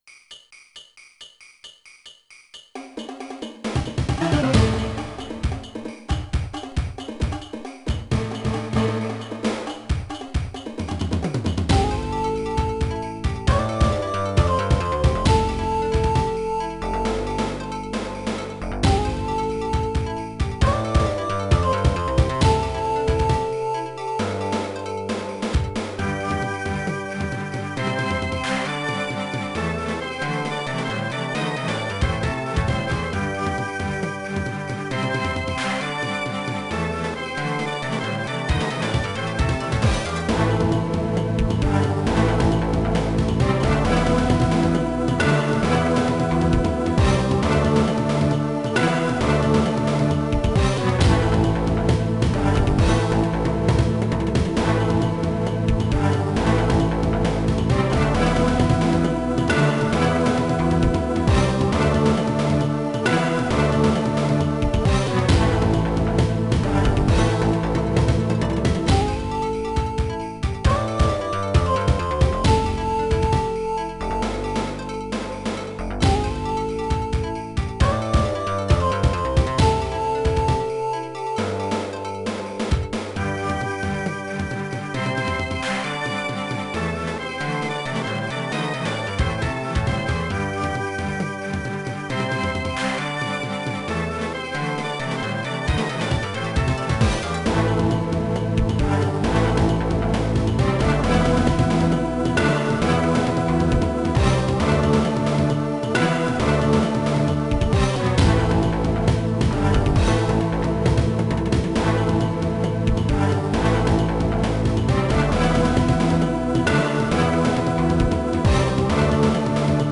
Stage one theme